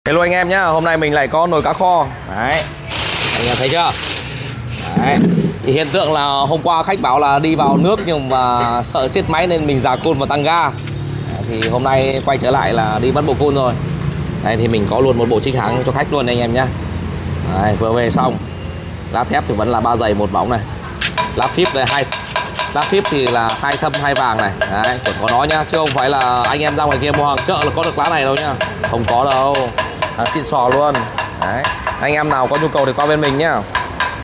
CBR 150 Rà Côn Tăng Sound Effects Free Download